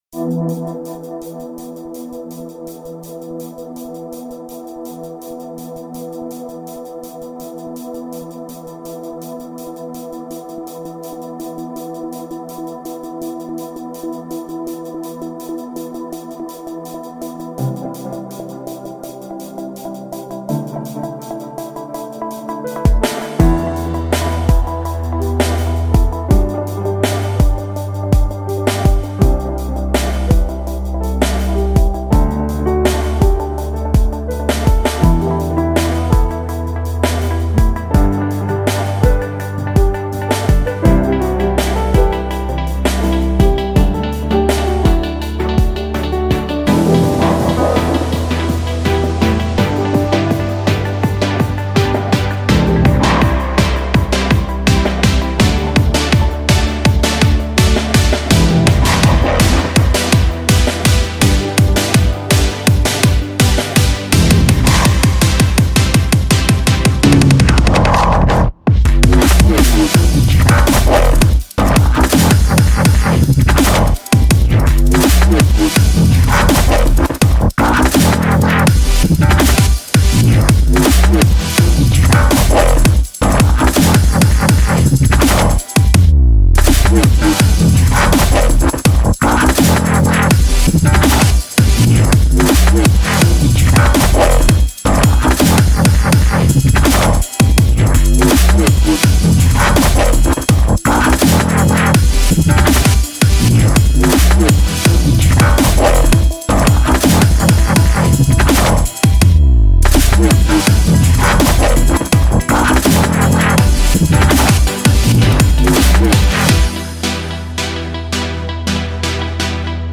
genre:neuro